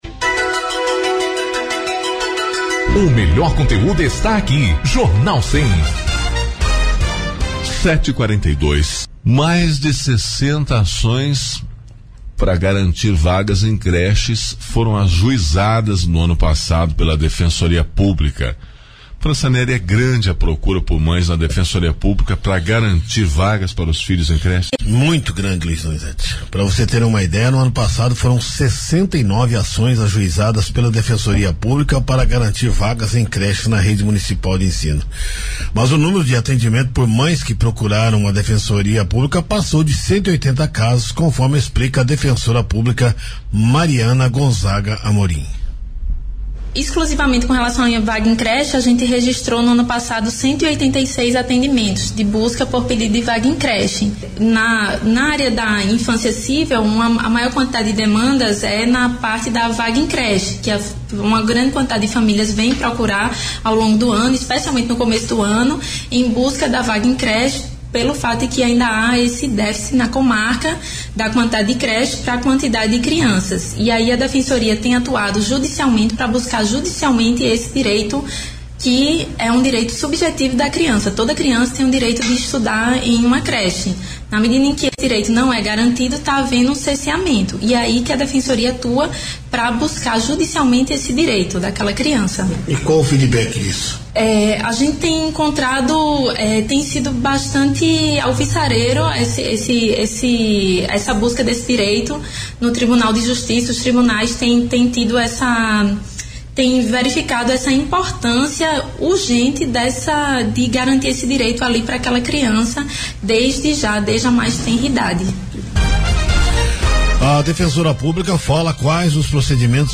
Entrevista da Defensora de Campo Mourão